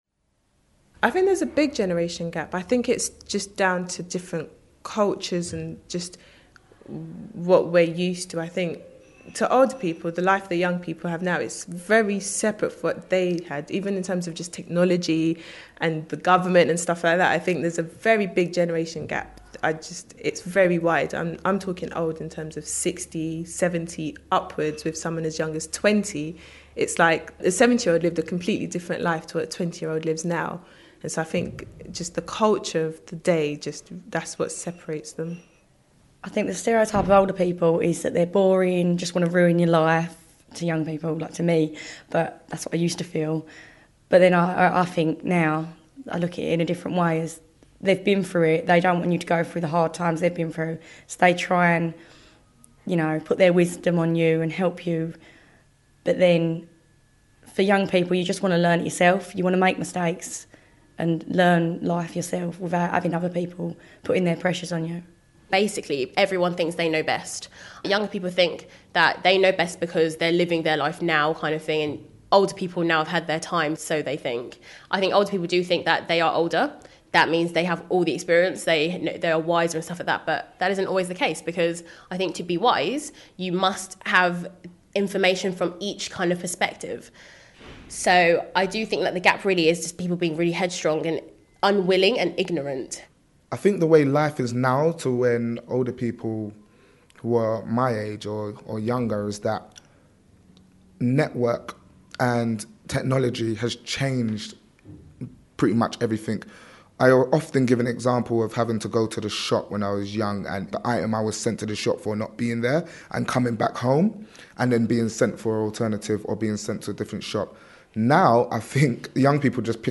In this audioboo recorded as part of the project young people shared their views.